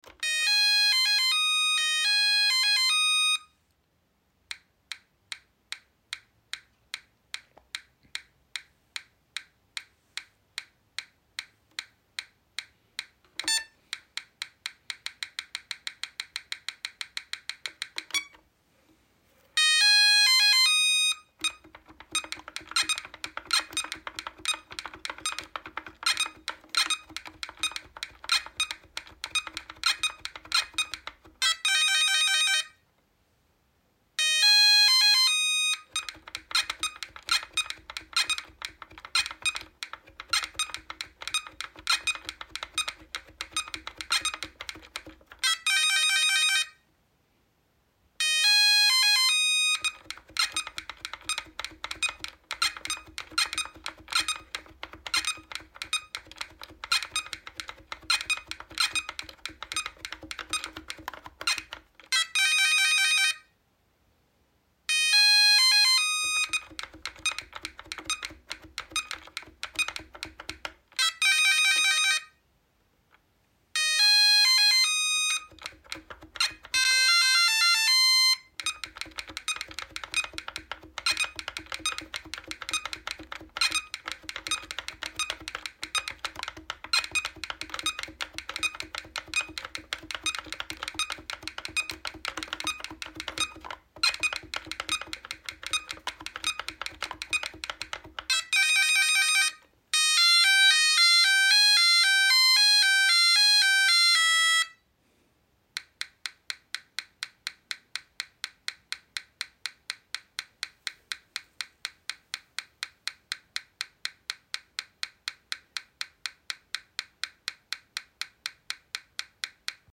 The sound of an original Grandstand Astro Wars table top console being played. The console is from 1981-82.